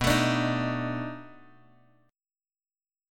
B7#9 Chord
Listen to B7#9 strummed